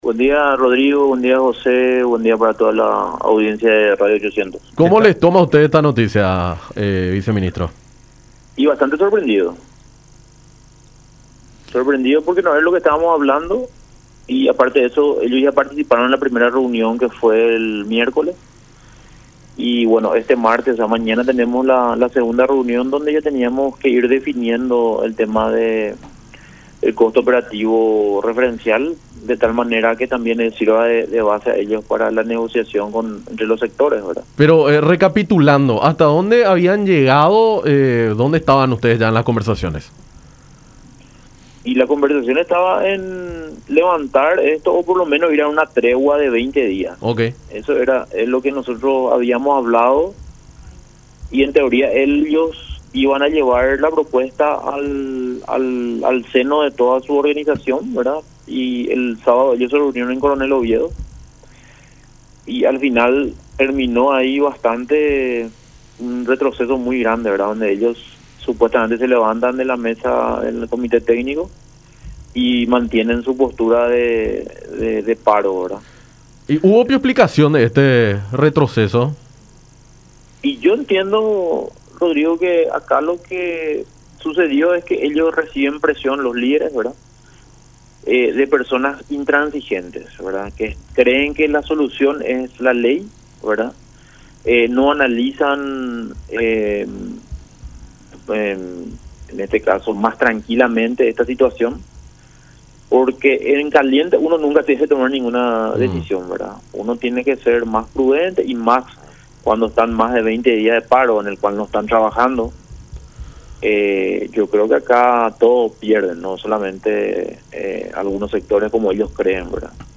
Tienen una postura intransigente”, manifestó Orué en diálogo con Enfoque 800 por La Unión.